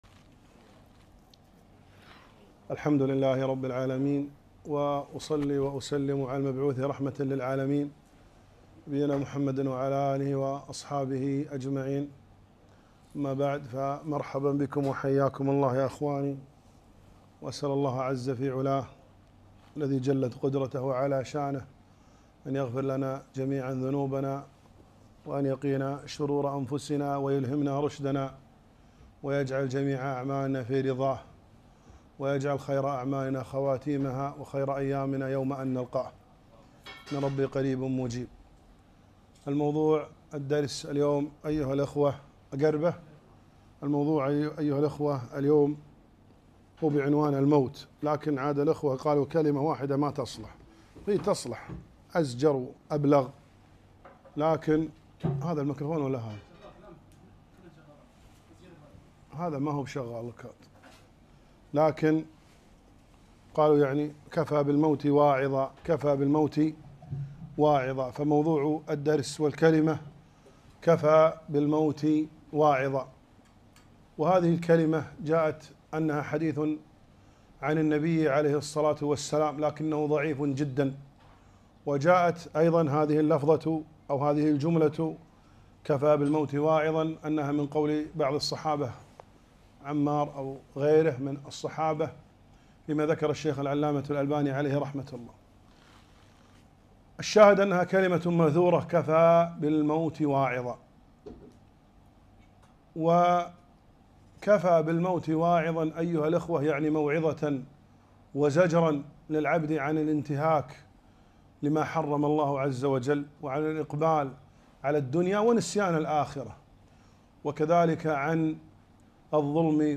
محاضرة - كفى بالموت واعظا